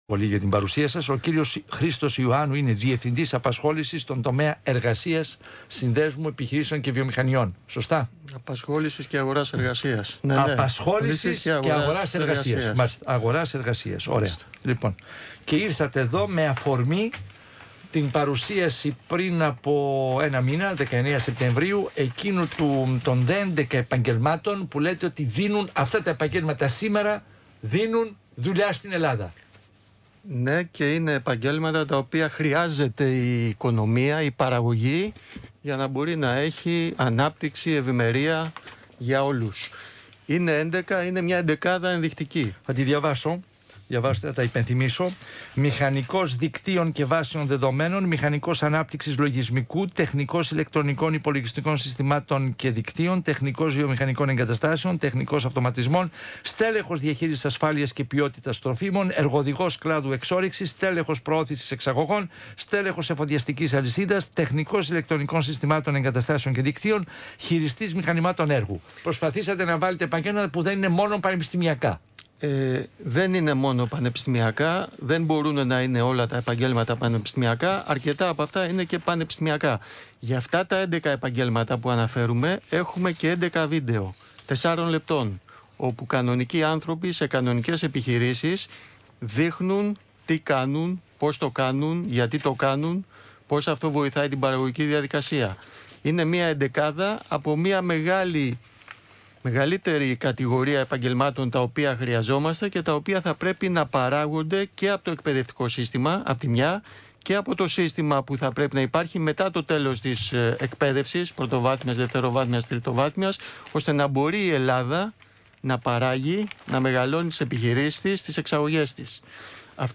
Σύγχρονες Δεξιότητες Συνέντευξη